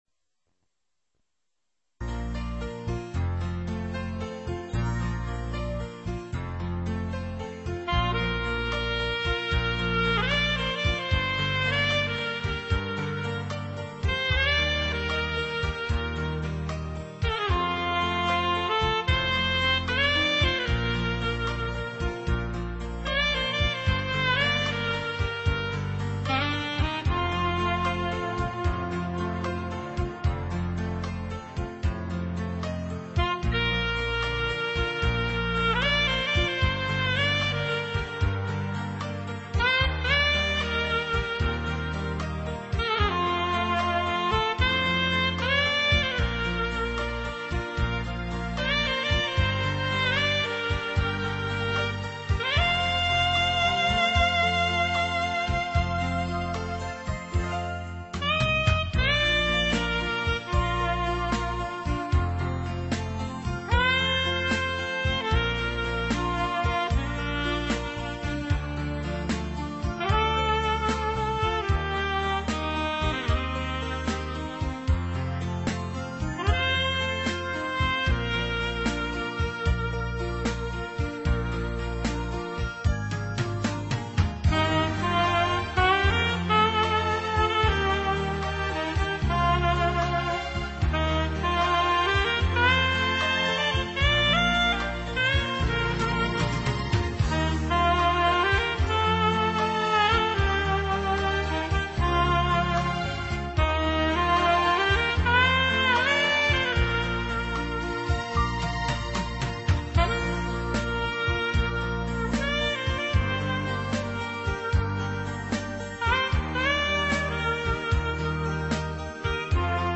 0612-萨克斯名曲茉莉花(2).mp3